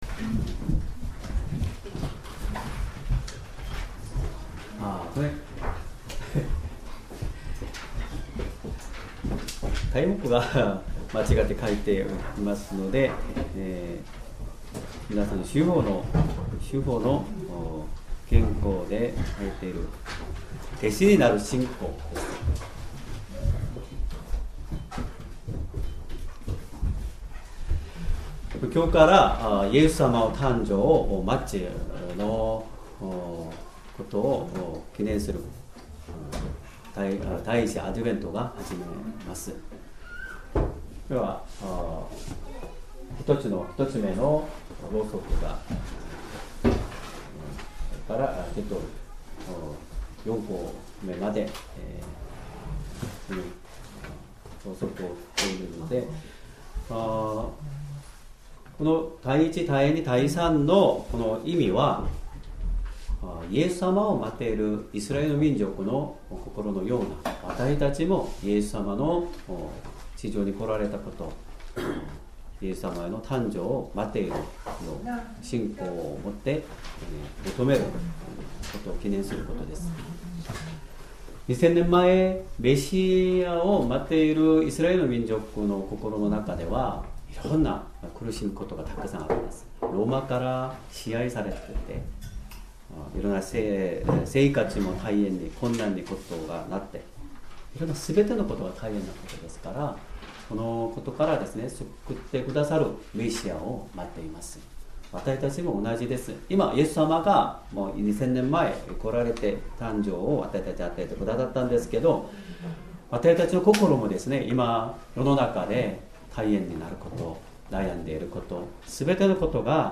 Sermon
2025年11月30日 主日礼拝 説教 「弟子になる信仰」 聖書 マタイの福音書8章 18-22節 8:18 さて、イエスは群衆が自分の周りにいるのを見て、弟子たちに向こう岸に渡るように命じられた。